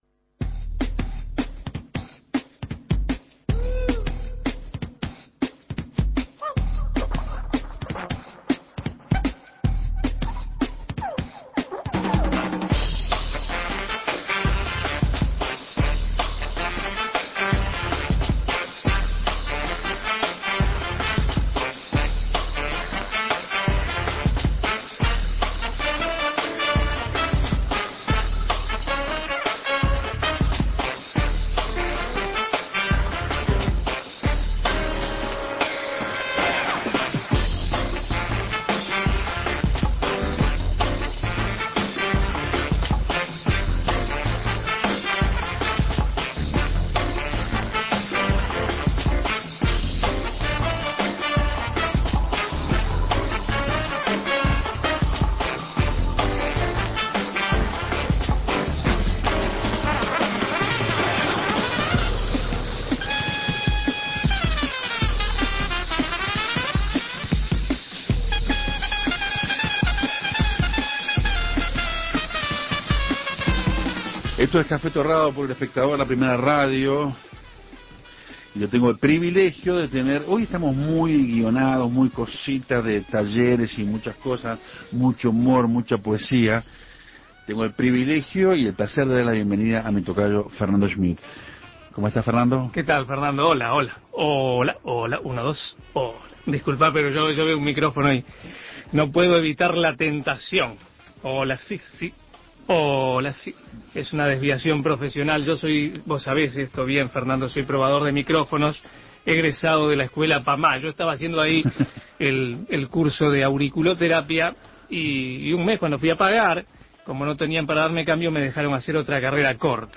Como no podía ser de otra manera, la entrevista fue con mucho humor y con sopresas.